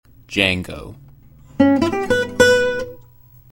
Django se pronuncia YANG-o. Rima con FANG-o. La «D» es muda.
muestra de audio de la pronunciación.